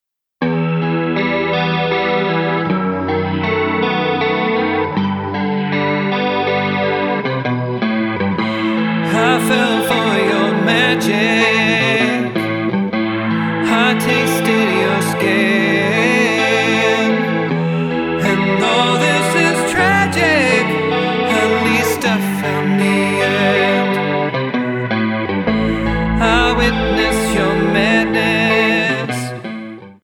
Tonart:Em Multifile (kein Sofortdownload.
Die besten Playbacks Instrumentals und Karaoke Versionen .